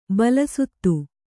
♪ bala suttu